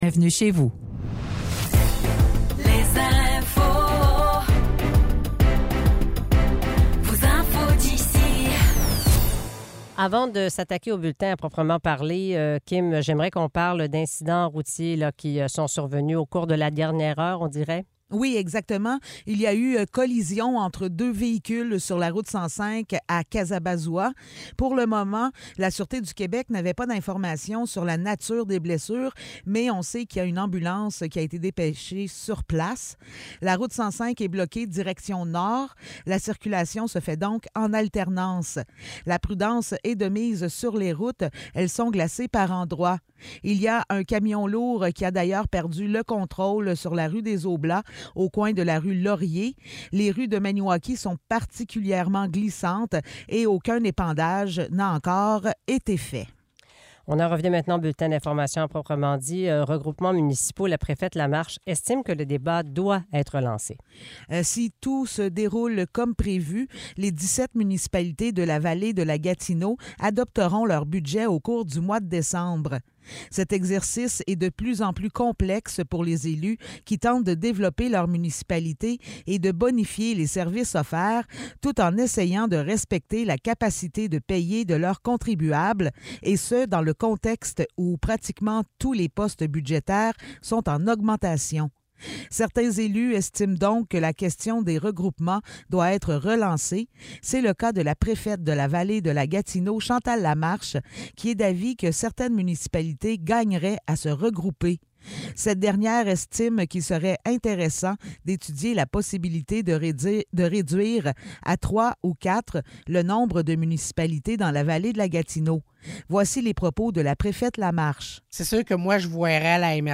Nouvelles locales - 9 novembre 2023 - 8 h